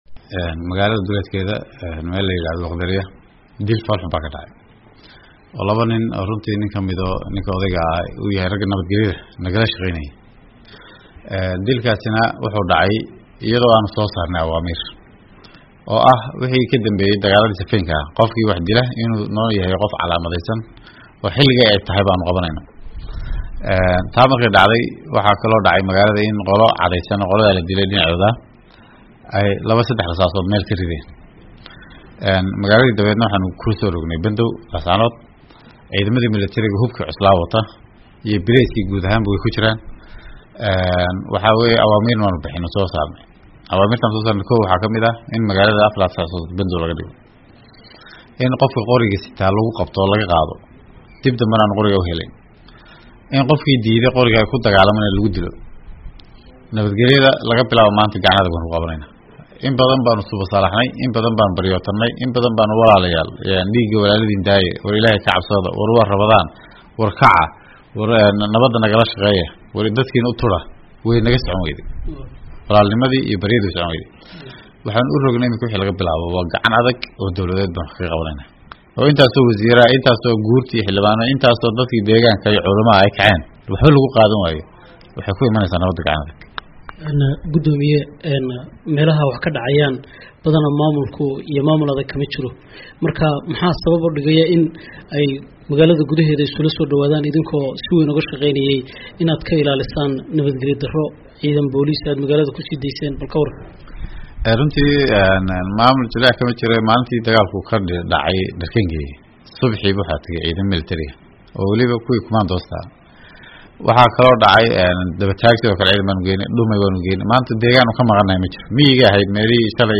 Wareysi: Guddoomiyaha gobolka Sool